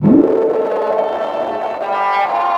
Index of /90_sSampleCDs/USB Soundscan vol.02 - Underground Hip Hop [AKAI] 1CD/Partition E/05-STRINGS